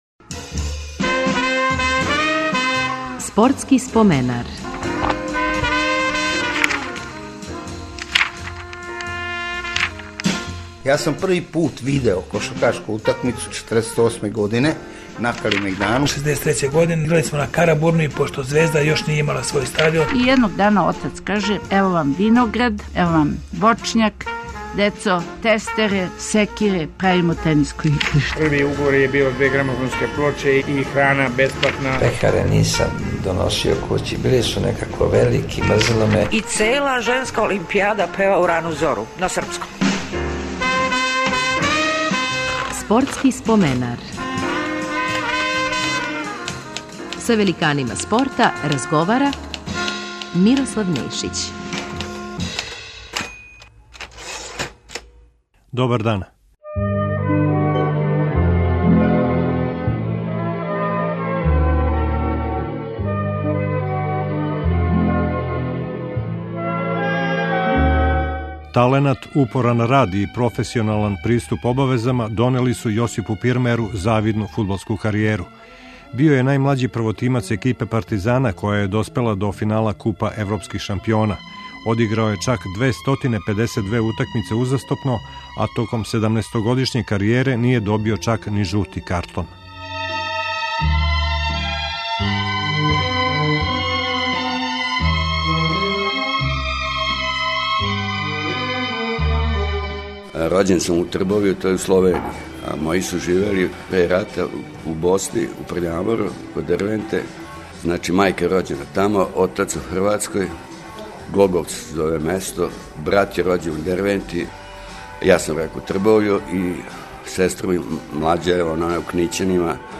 Гост Споменара је фудбалер Јосип Пирмајер.